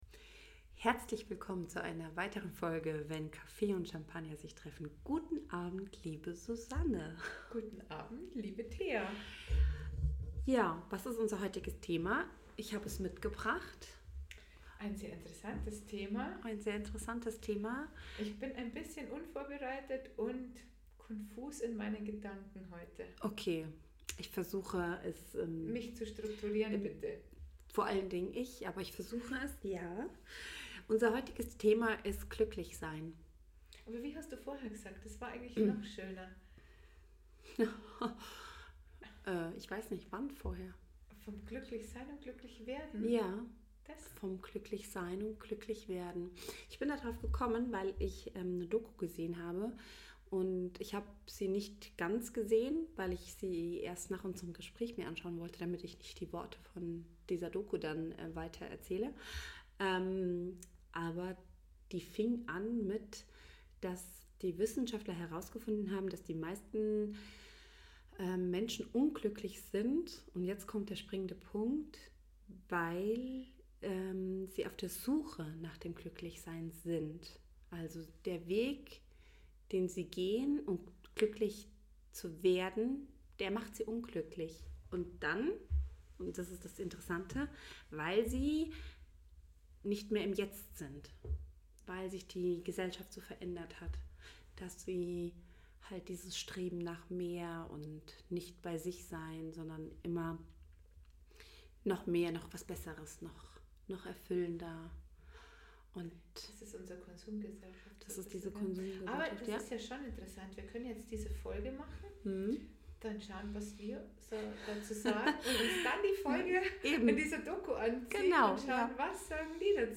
Erweiterte Suche Macht Schokolade glücklich? vor 2 Monaten Was macht uns glücklich? 54 Minuten 49.14 MB Podcast Podcaster Wenn Kaffee und Champanger sich treffen Zwei Frauen die dich in ihre Gedanken mitnehmen.